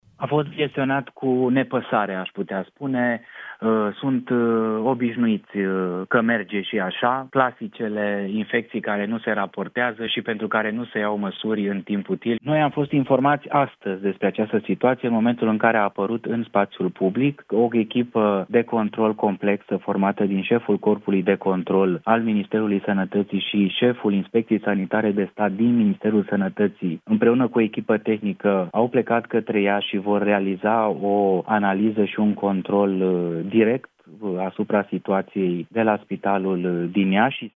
„Au gestionat cu nepăsare! Cel mai probabil, vor fi dați afară!”, a declarat la Europa FM ministrul Sănătății, Alexandru Rogobete, despre conducerea Spitalului de Copii „Sfânta Maria” din Iași, după ce șase copii au murit la secția ATI, infectați cu bacteria Serratia marcescens.